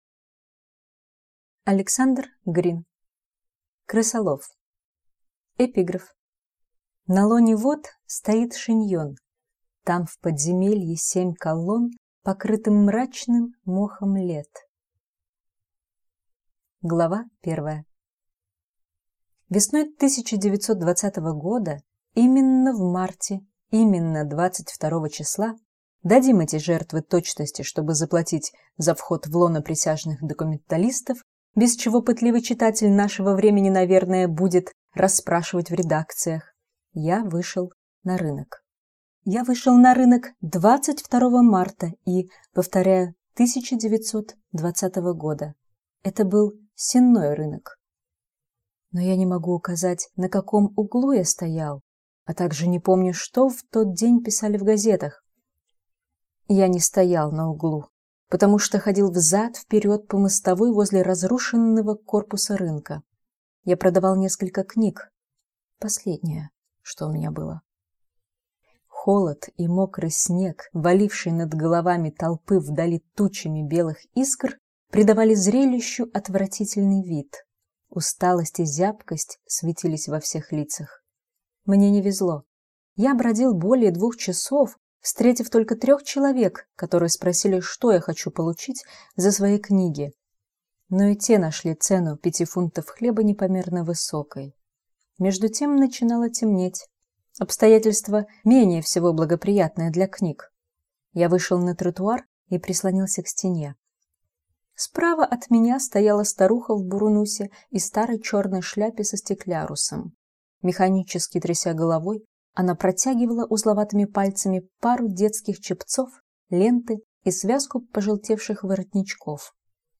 Аудиокнига Крысолов | Библиотека аудиокниг